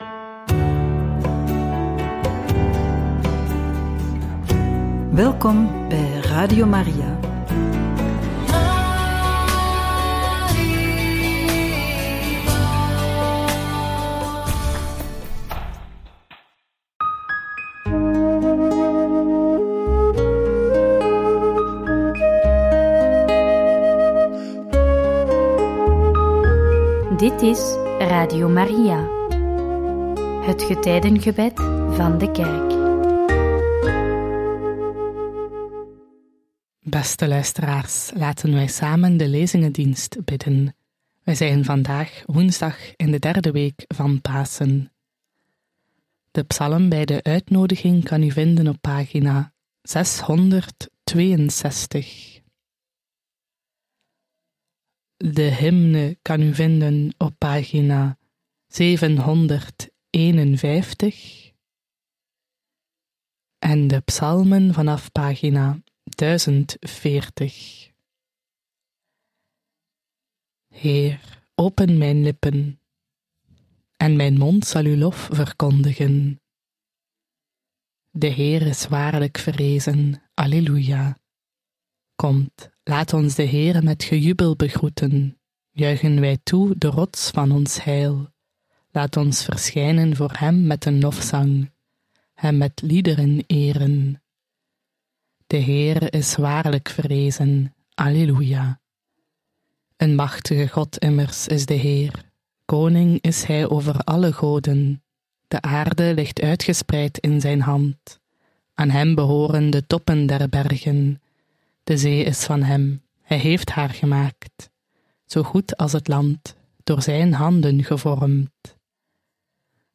Lezingendienst